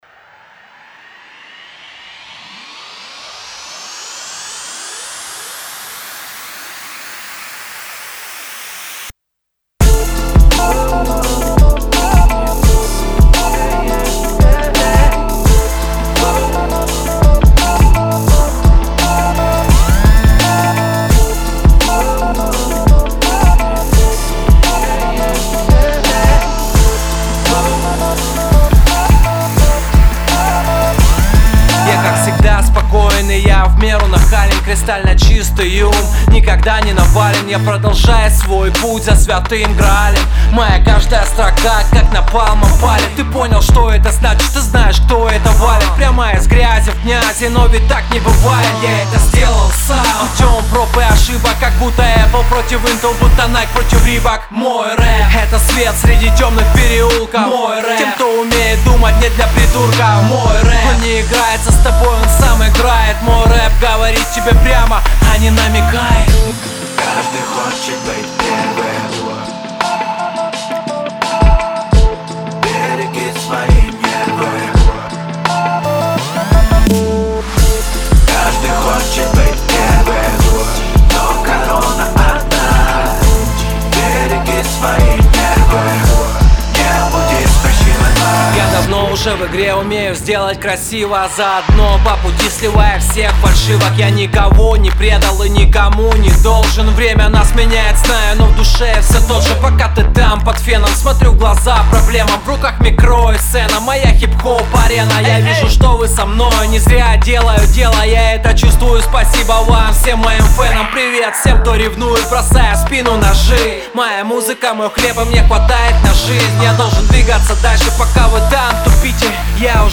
Русский рэп 2025, Русские треки, 2026